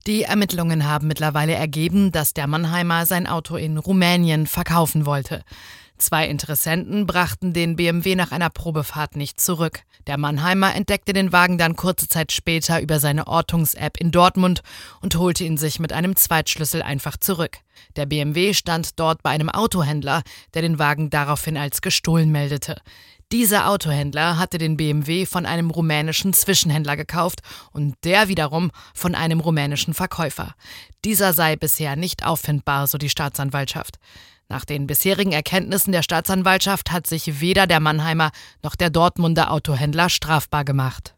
Ja, klingt komplett verwirrend - im Audio versucht meine Kollegin das alles noch einmal einzuordnen und sagt auch, was jetzt mit dem Wagen passiert: